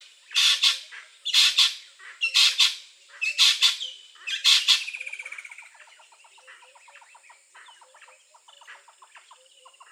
• natal spurfowl - bearded woodpecker.wav
natal_spurfowl_-_bearded_woodpecker_bzg.wav